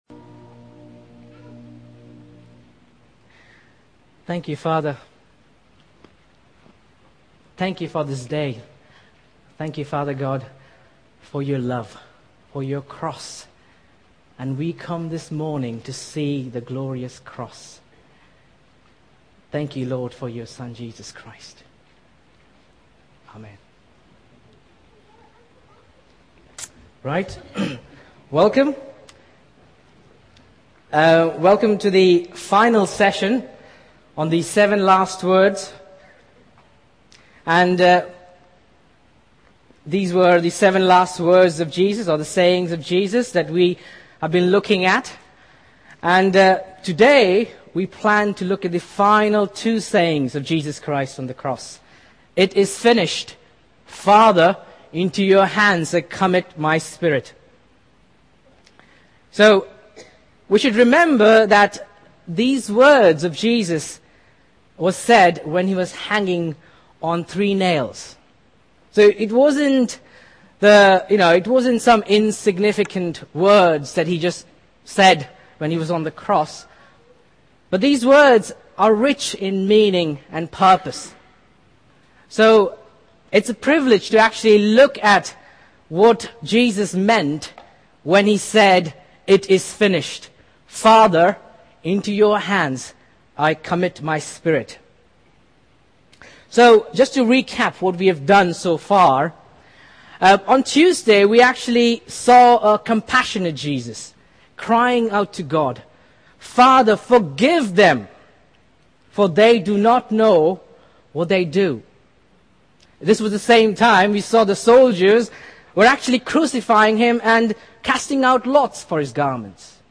Sermons from Emmanuel Church Durham's Easter 2014 series on Jesus' seven last words on the cross.